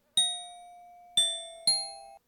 Hovering_osprey.ogg